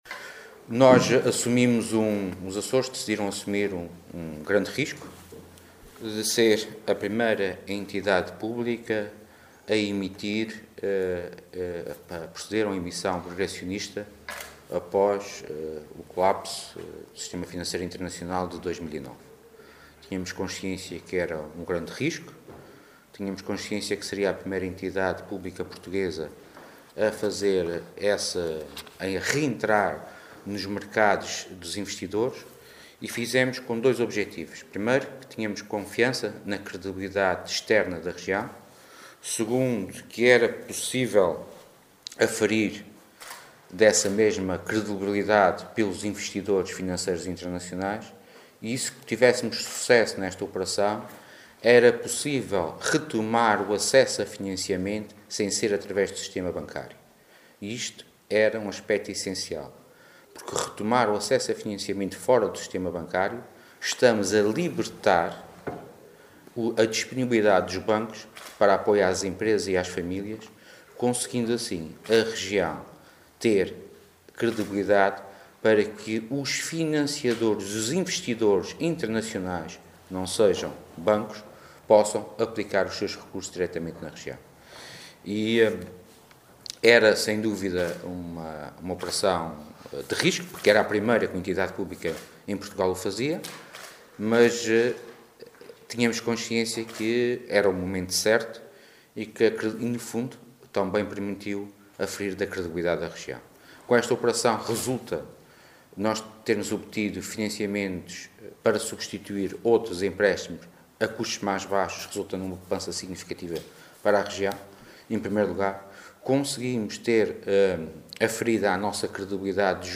Sérgio Ávila falava numa conferência de imprensa conjunta com o Banco Finantia, entidade responsável pela operação, que registou forte procura.